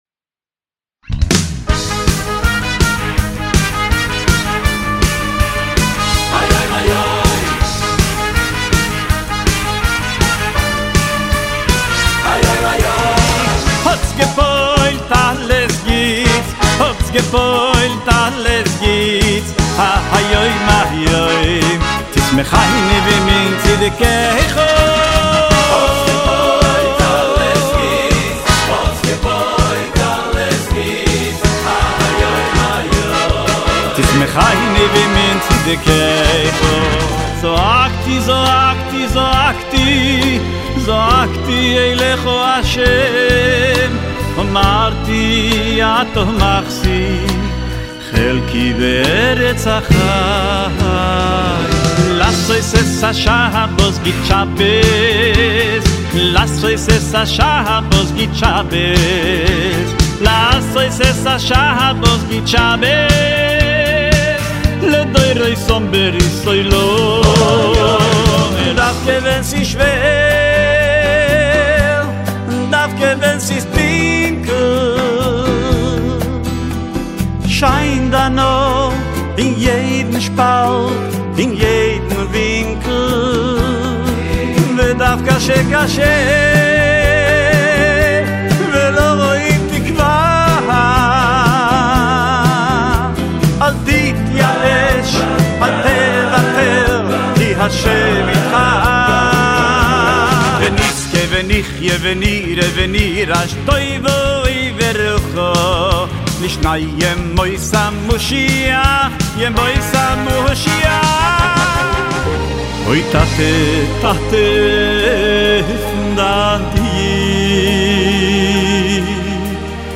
באלבום 10 רצועות ביניהן קצביות ומרגשות
לחצו PLAY להאזנה למיקס משירי האלבום
שירים חסידיים